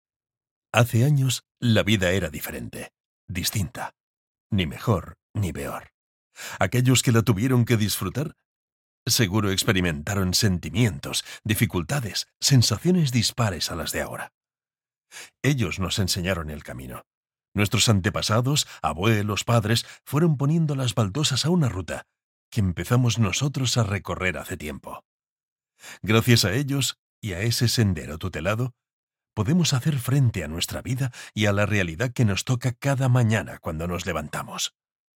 Spanish Male Voice Over Artist
Male
Authoritative, Confident, Corporate, Friendly, Natural, Smooth, Warm
Castillian (Spanish from Spain) and Catalan speaker voice over.
Microphone: Brauner Phanthera
Audio equipment: Apple Macintosh Quad Intel i7 Processor, ProTools Studio, Universal Audio Apollo Quad audio interface, iCon Qcon Pro G2 Control surface, Universal Audio LA-610 MkII mic pre-amp, Brauner Phanthera studio microphones, sound booth room.